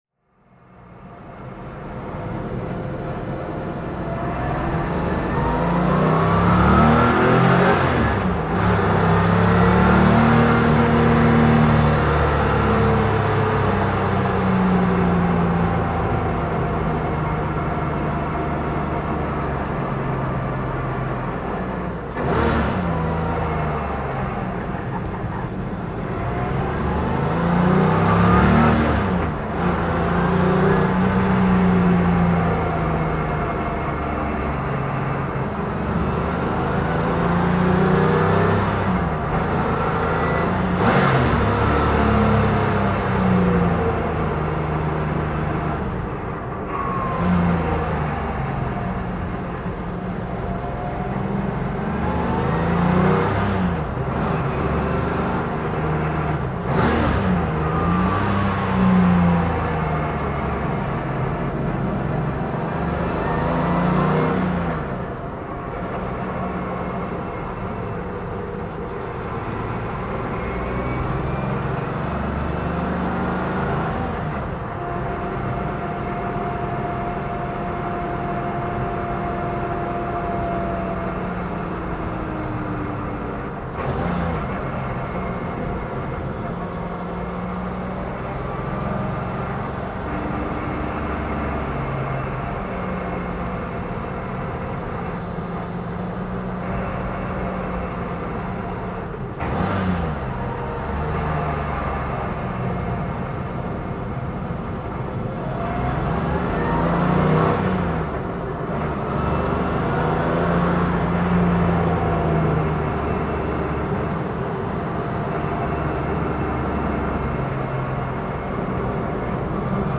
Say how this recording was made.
Porsche 964 Carrera 2 Cup (1991) - Onboard